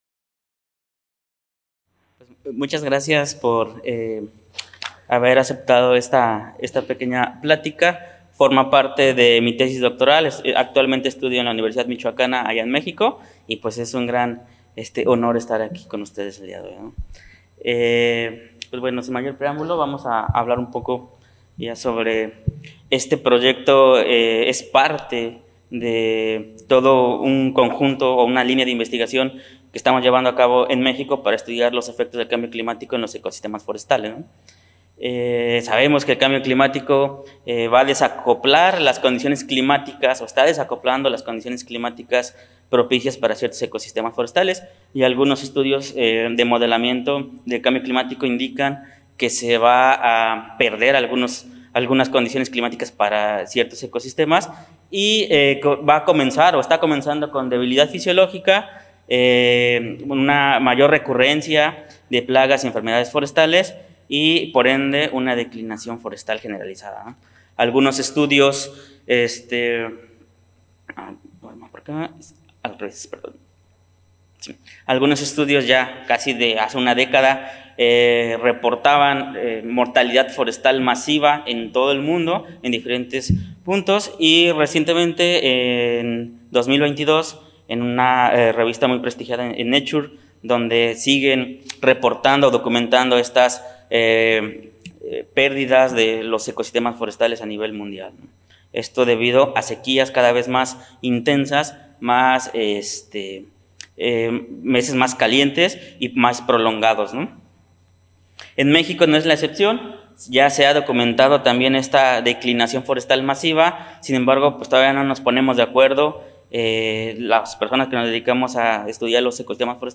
En aquesta conferència es presenta l'estudi fet sobre com pot afectar el canvi climàtic a la massa forestal mexicana. S'explica com es van utilitzar drons amb sensors multiespectrals per calcular índexs de vegetació i estat dels arbres, sobretot dels pins (Pinus hartwegii) ubicats al centre de Mèxic.